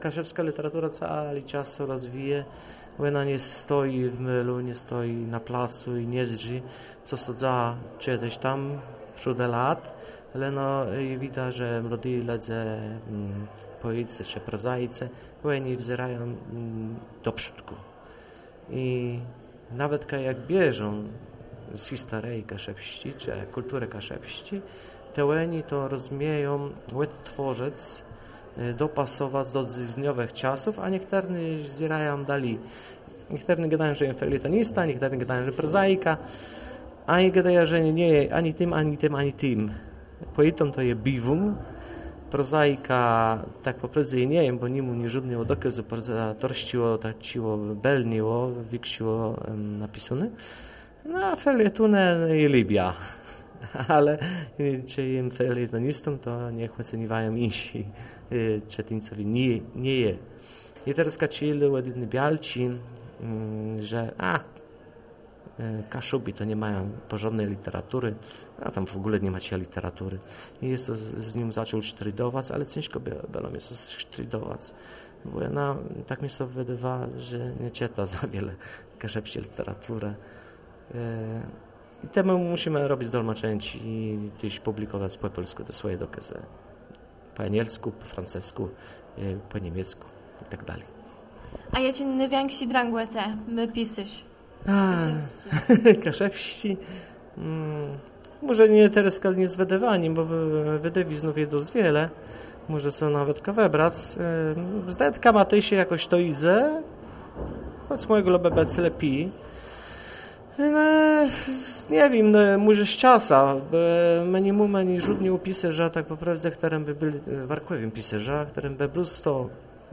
Kaszuby północne
Starzyno
Język kaszubski « Kaszuby północne